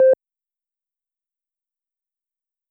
start_beep.wav